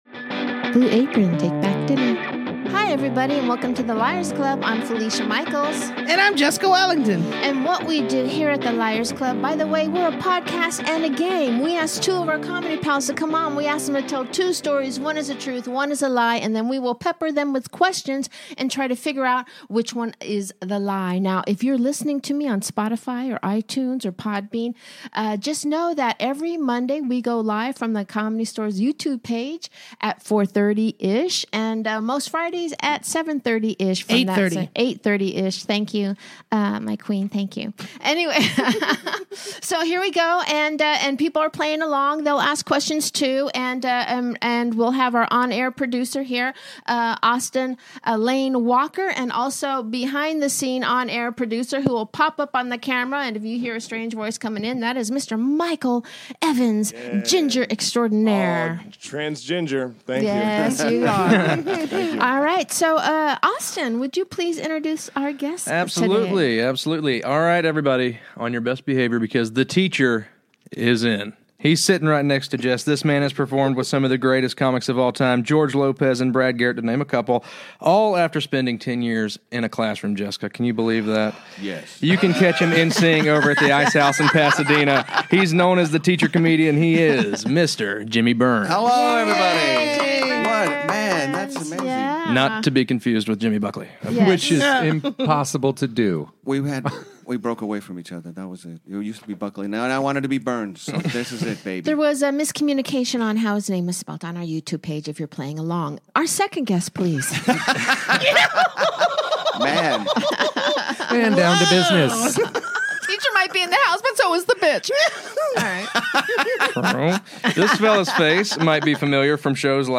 Comedians